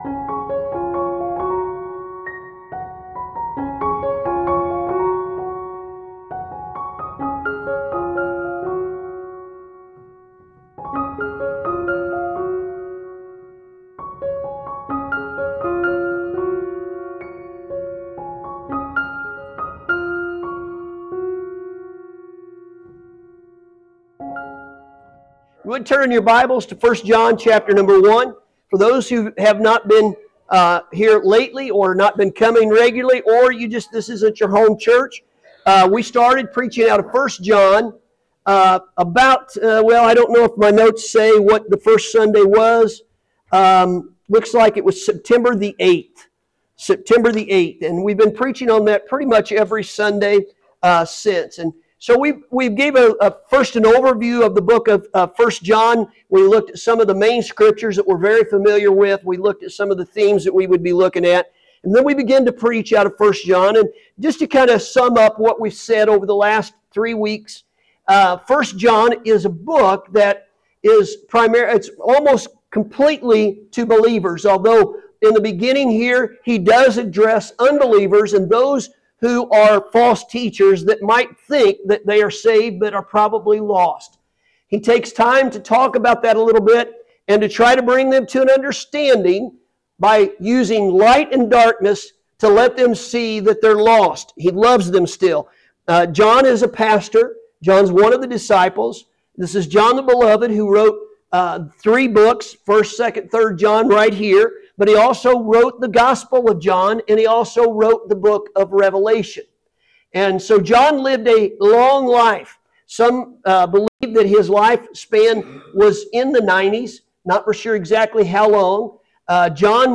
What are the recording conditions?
Sunday Morning – September 29th, 2024